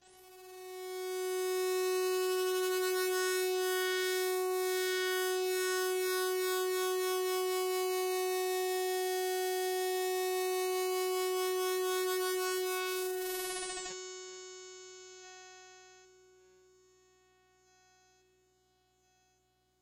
标签： F6 midinote90 SequntialMAX synthesizer singlenote multisample
声道立体声